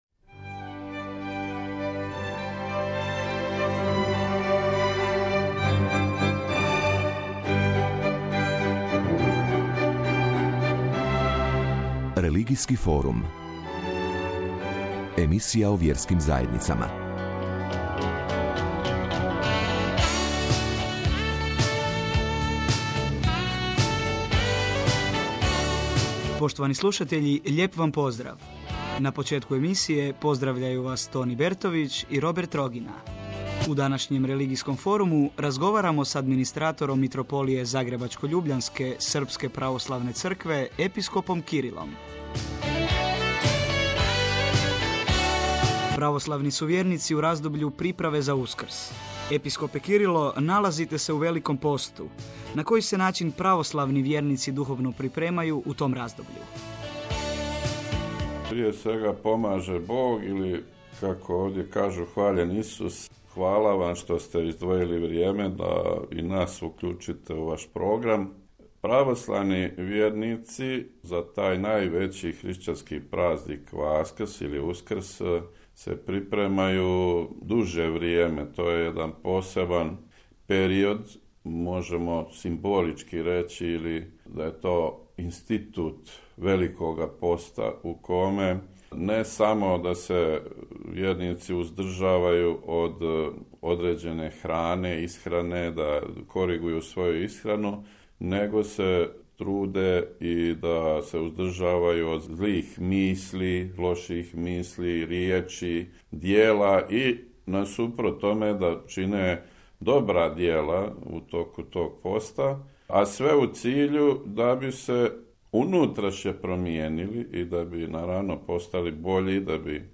O Великом посту, препреми православних хришћана за Васкрс и другим темама Епископ Кирило говорио је у интервјуу датом Хрватском радију.
Емисија Религијски форум емитована је 3. априла на Хрватском радију.